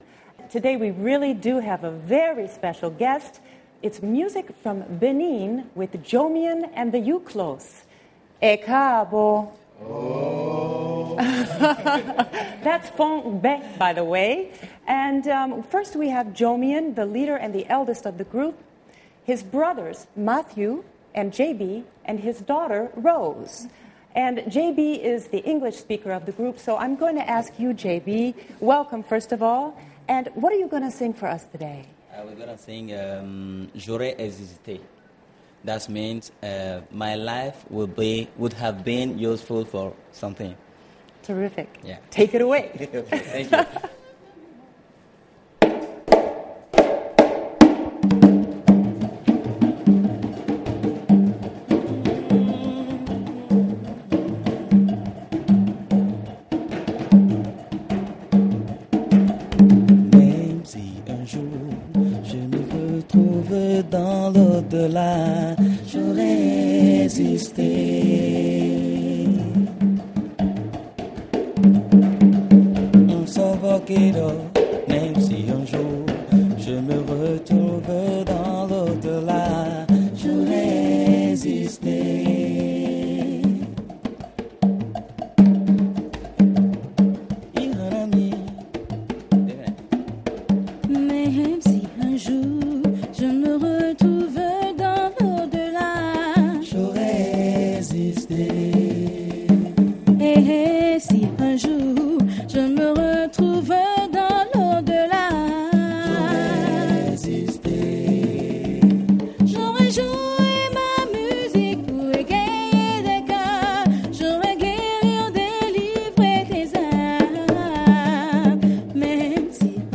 perform jazz with a soft bossa nova beat